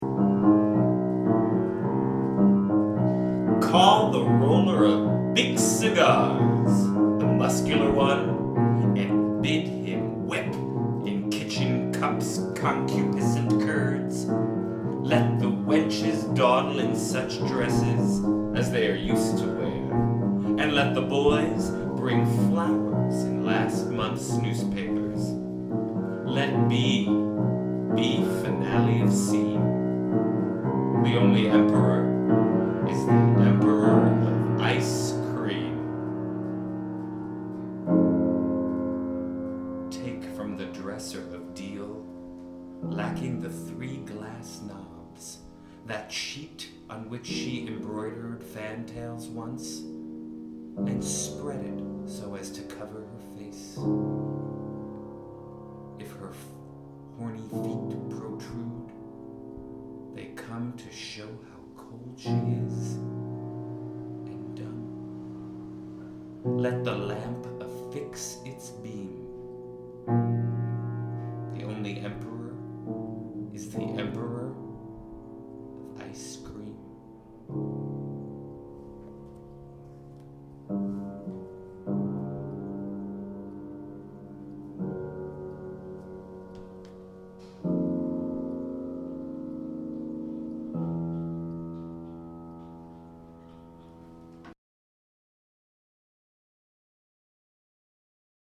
vox
piano